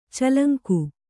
♪ calaŋku